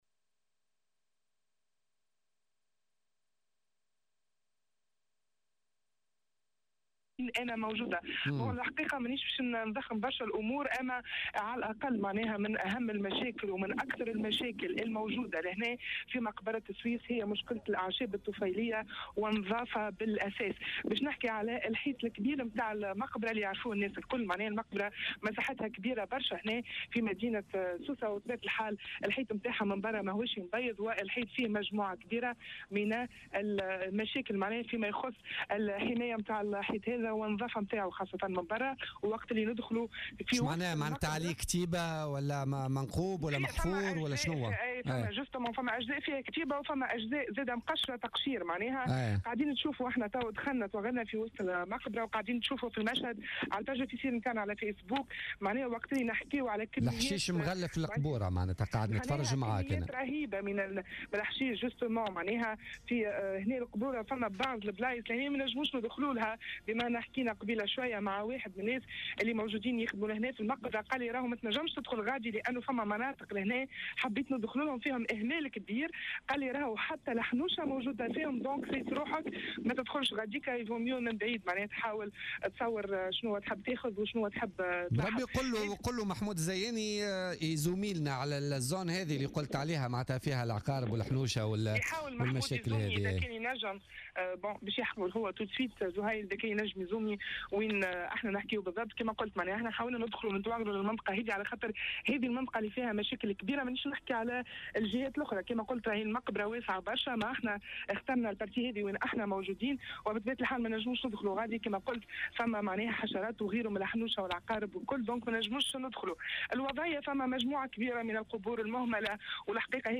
تحول فريق الرادار اليوم الجمعة إلى مقبرة سويس بسوسة بعد تلقي الجوهرة "اف ام" عدة تشكيات من سكان المنطقة من الوضعية الكارثية التي باتت عليها المقبرة في غياب أي تدخل بلدي .